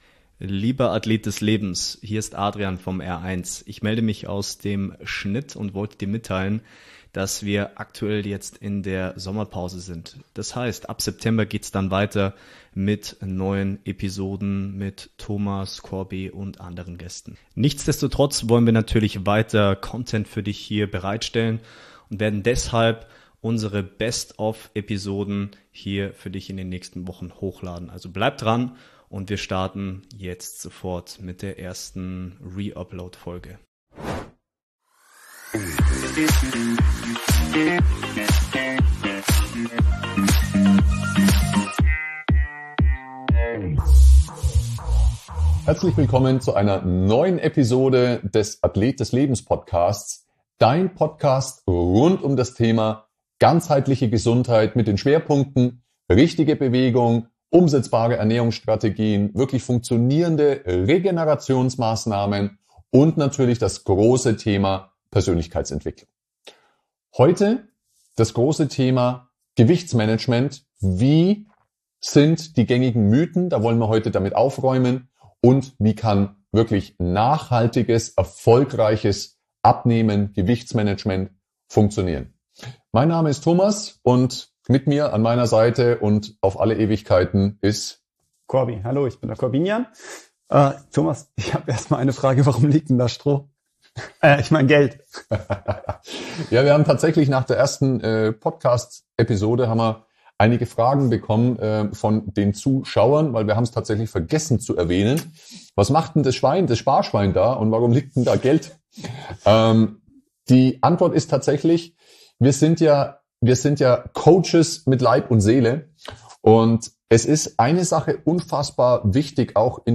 Eine Mischung aus fachlicher Kompetenz, authentischen Plauderei aus dem Nähkästchen und einer (ganzheitlich) gesunden Portion Spaß.